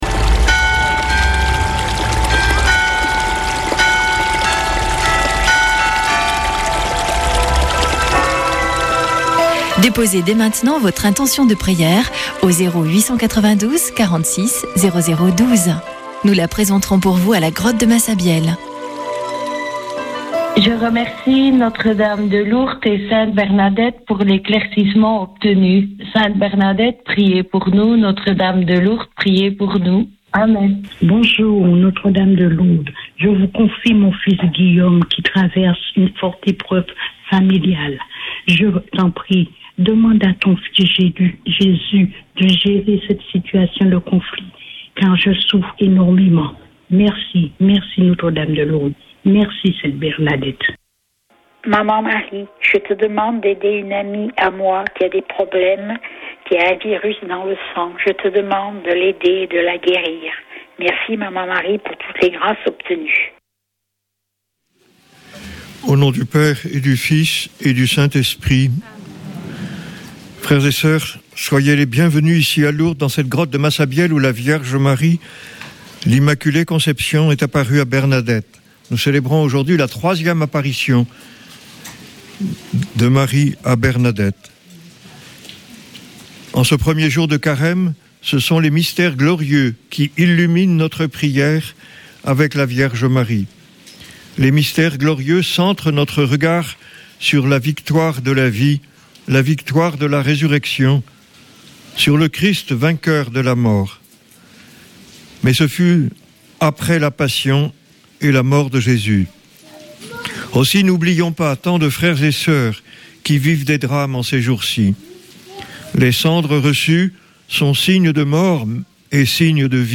Accueil \ Emissions \ Foi \ Prière et Célébration \ Chapelet de Lourdes \ Chapelet de Lourdes du 18 févr.
Une émission présentée par Chapelains de Lourdes